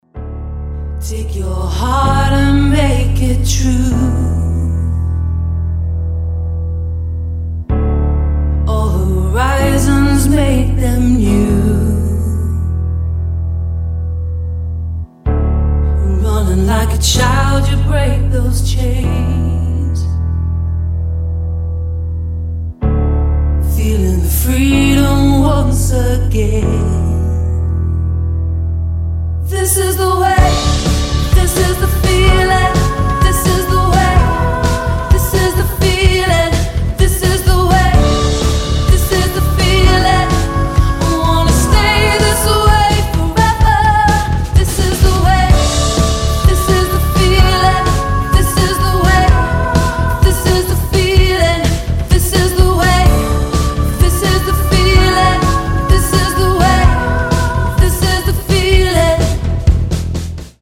• Качество: 256, Stereo
красивые
vocal
саундтрек
пафосная